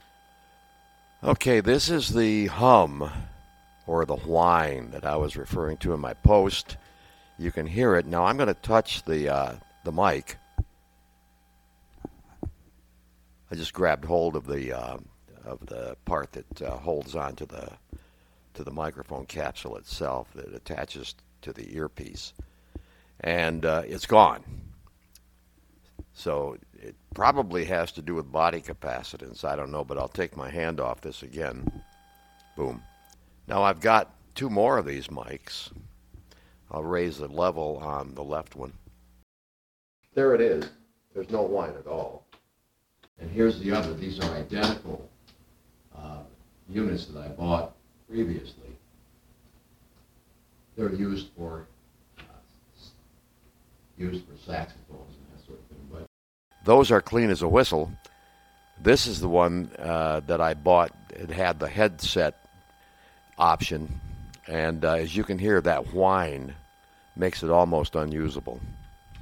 Whine in wireless mic
The ones for the horns work fine, but the headset mic has a high pitched whine. When I touch it the whine disappears, which I guess means it has something to do with body capacitance or grounding.